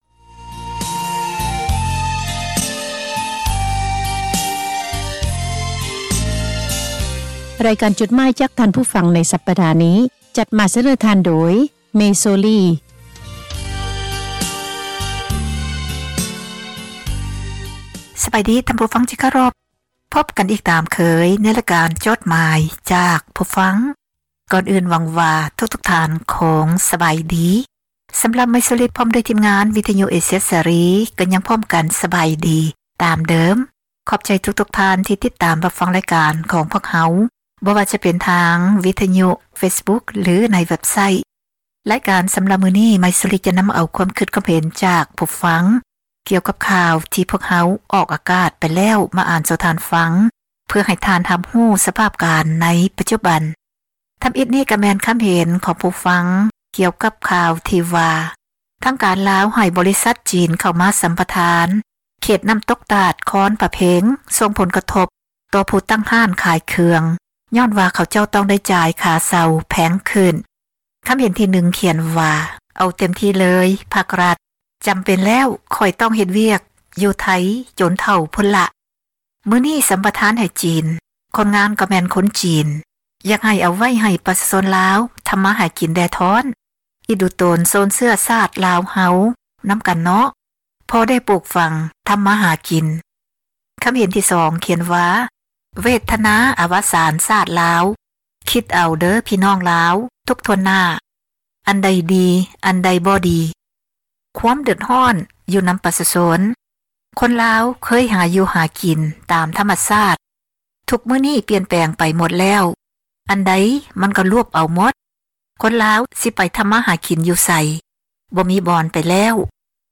ຕິດຕາມດ້ວຍ ລຳລ່ອງ "ຂ້າມຂອງ" …